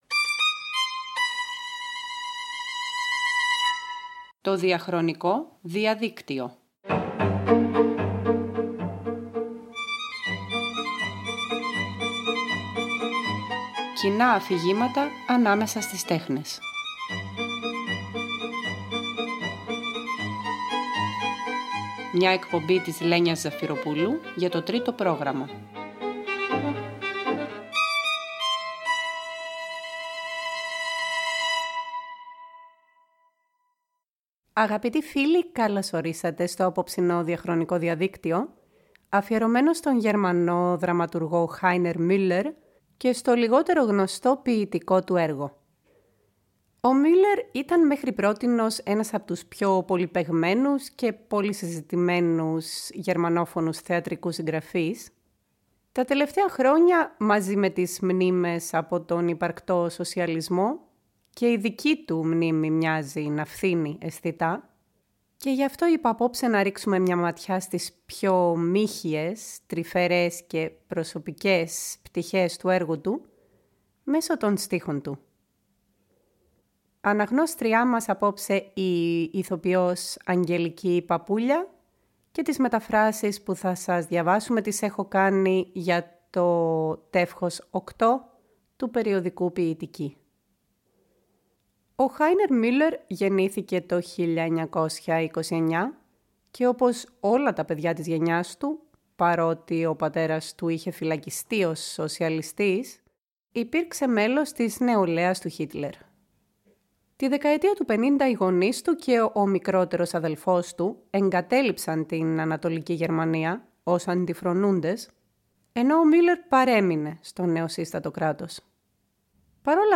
Παρέα με την ηθοποιό Αγγελική Παπούλια, διαβάζουμε τα ποιήματά του που αποκαλύπτουν τις πιο μύχιες, τρυφερές πτυχές του, συνοδευόμενες πάντα από ένα απαρηγόρητο, ειρωνικό μειδίαμα.